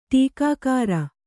♪ ṭīkākāra